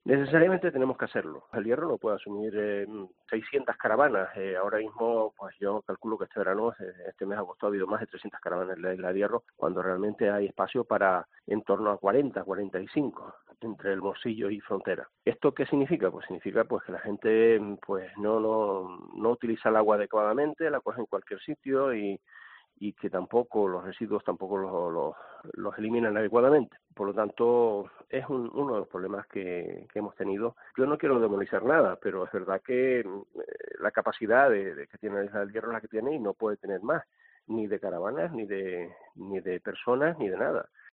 Alpidio Armas, presidente del Cabildo de El Hierro